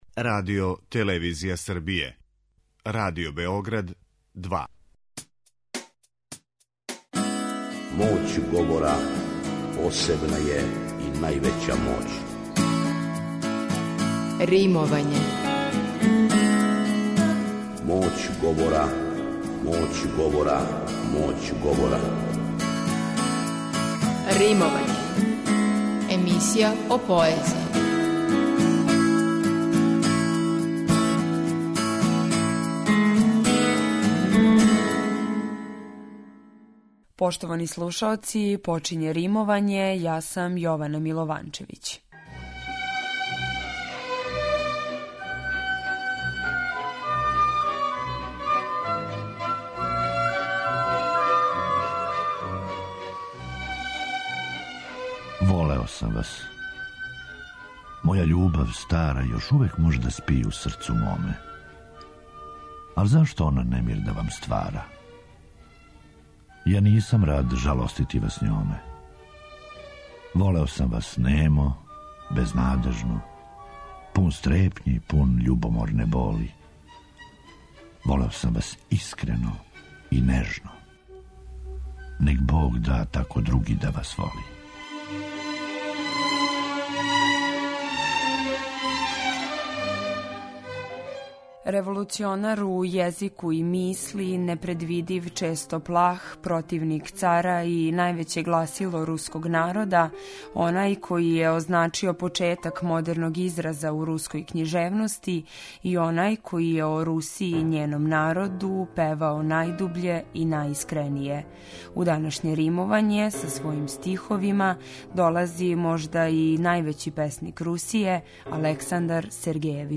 Чуће се и музика из опере „Евгеније Оњегин".